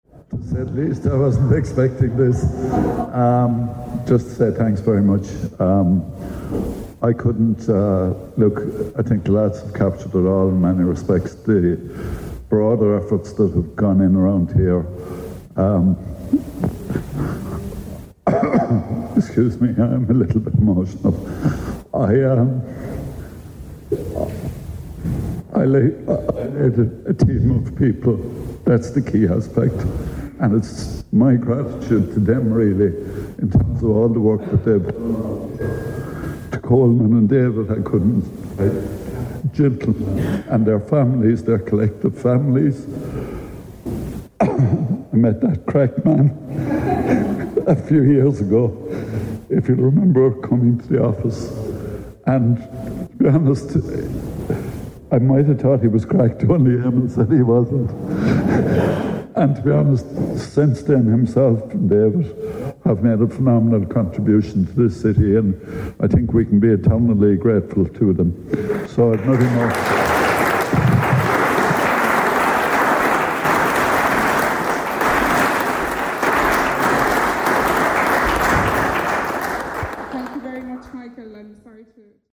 Mr. Walsh attended one of his last official functions yesterday at the Irish Museum of Time, for the announcement of the expansion of the facility into the adjacent Central Arts Hall.
You can also listen to Michael Walsh’s speech at the event below.